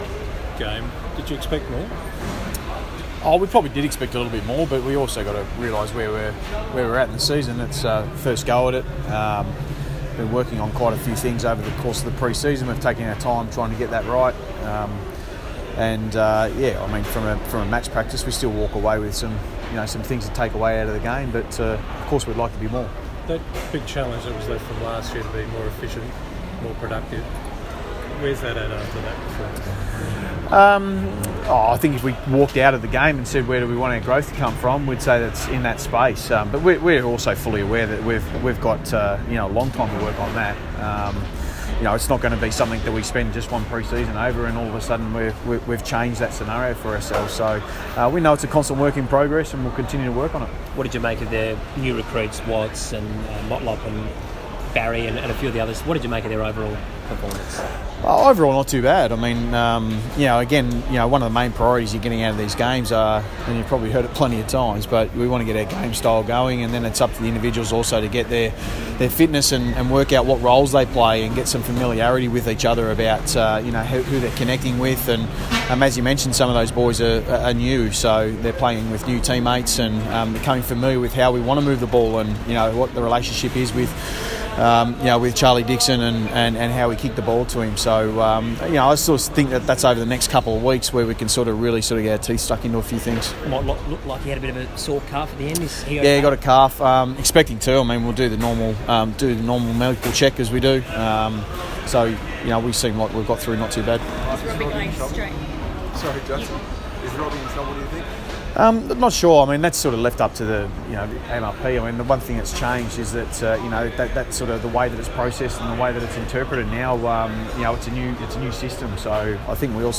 Michael Voss press conference - Monday, 26 February, 2018
Michael Voss talks with media after arriving back in Adelaide following the opening round JLT Community Series clash with West Coast.